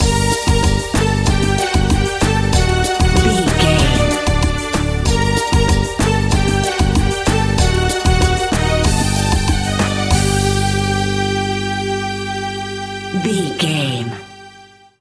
Aeolian/Minor
World Music
percussion
congas
bongos
djembe
marimba